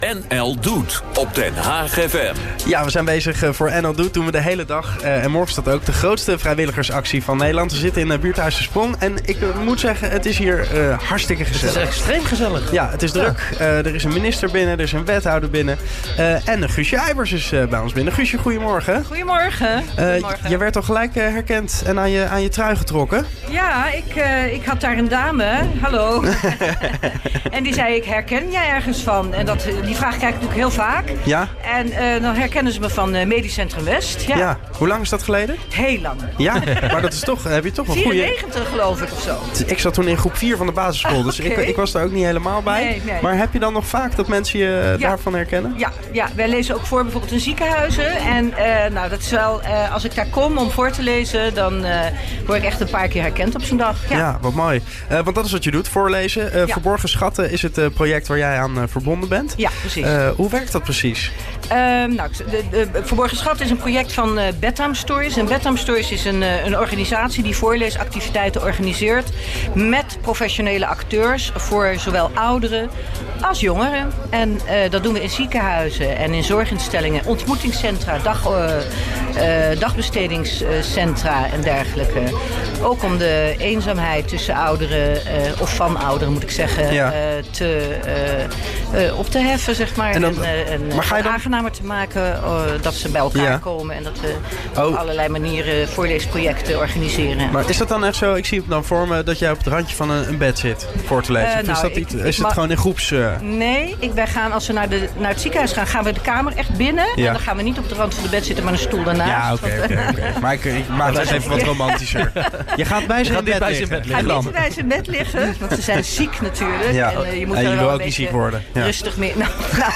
te gast bij Den Haag FM ter gelegenheid van NL Doet 2018